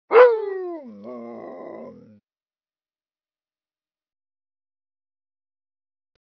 دانلود آهنگ زوزه سگ هاسکی از افکت صوتی انسان و موجودات زنده
جلوه های صوتی
دانلود صدای زوزه سگ هاسکی از ساعد نیوز با لینک مستقیم و کیفیت بالا